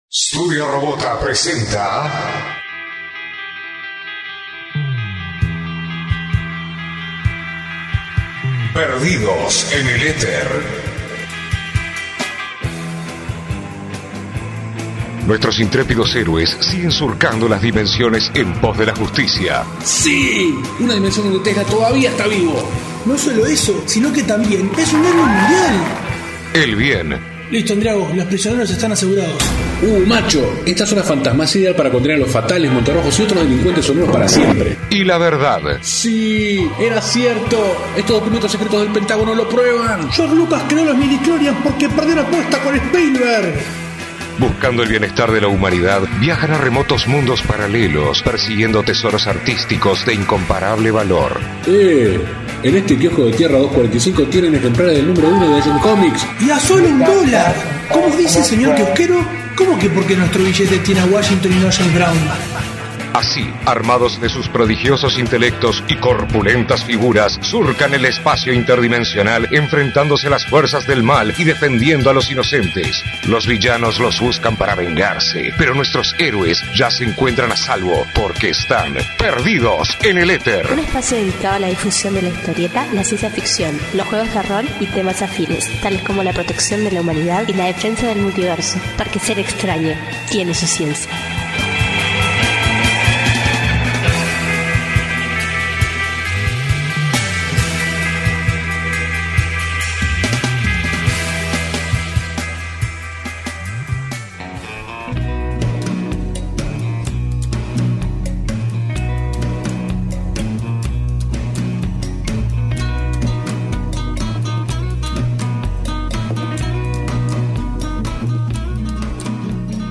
Quedó un programa GIGANTE, con muchos invitados.